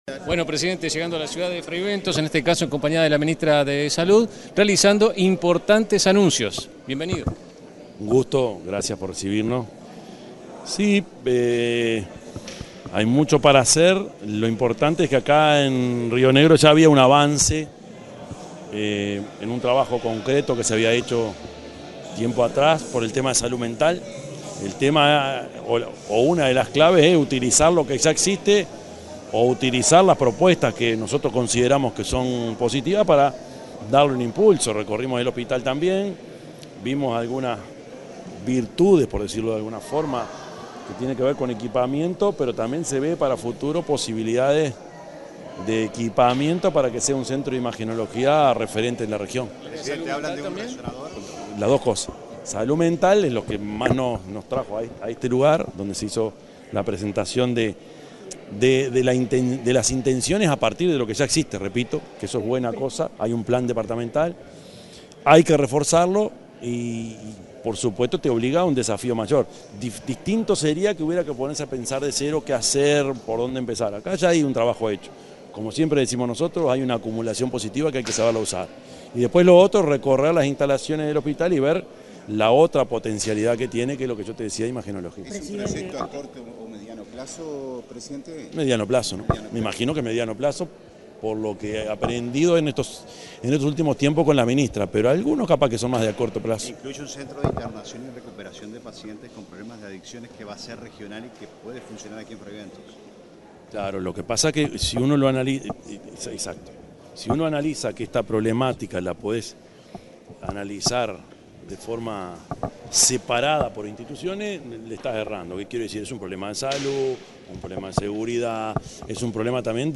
Declaraciones del presidente Orsi en Fray Bentos
El presidente de la República, profesor Yamandú Orsi, dialogó con la prensa en Río Negro, en una segunda instancia de su recorrida por el departamento